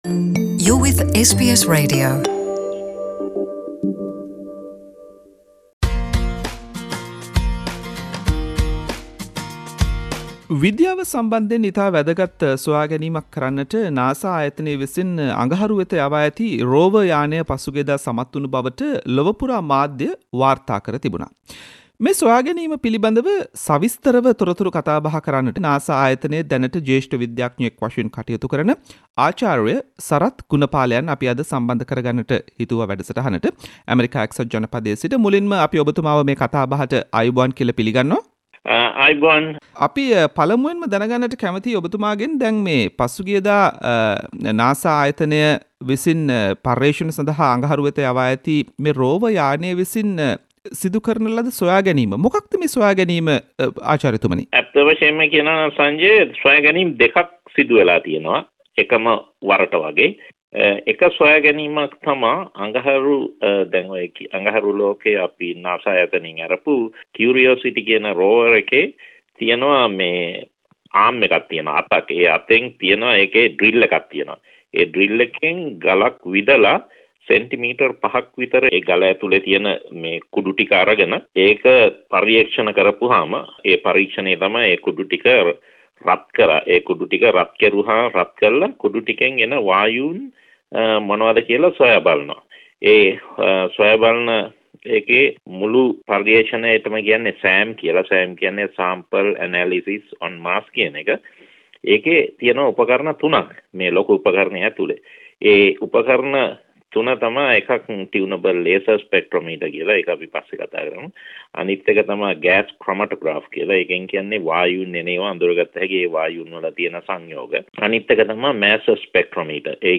Special interview with senior scientist at NASA